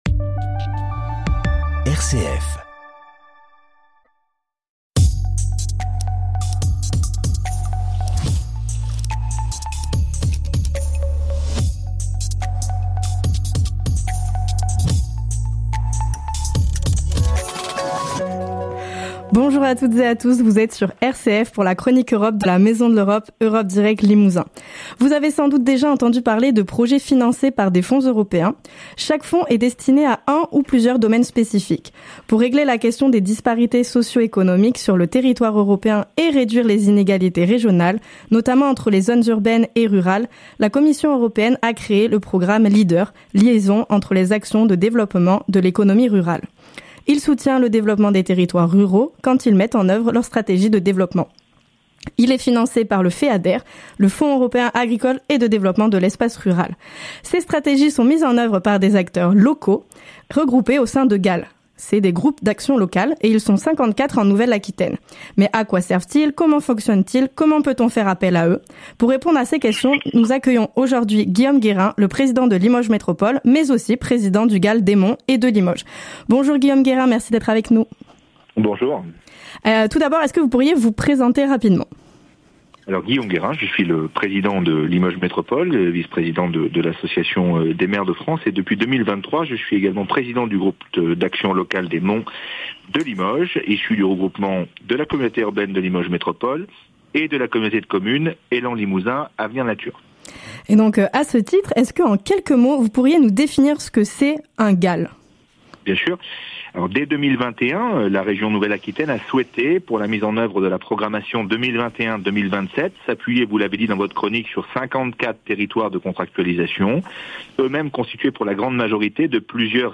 Pour répondre à nos questions, nous faisons appel à notre invité Guillaume Guérin, le Président de Limoges Métropole, aussi Président du Gal des Monts et de Limoges.